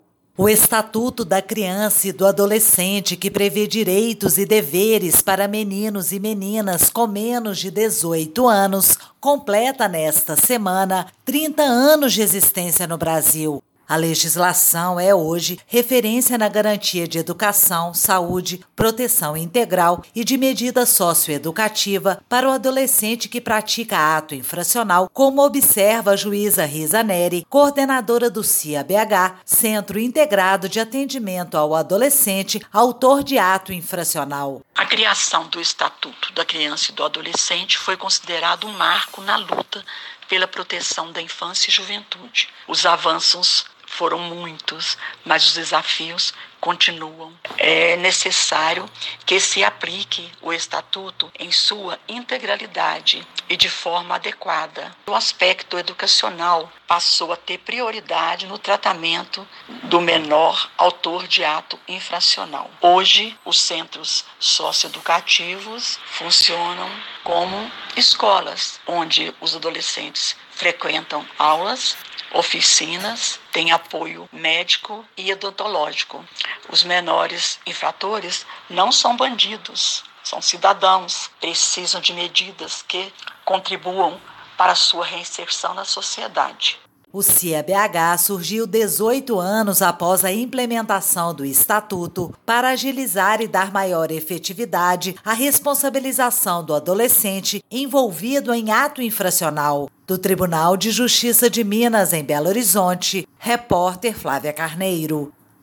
Ouça o podcast com o áudio da juíza Riza Nery, coordenadora do CIA-BH: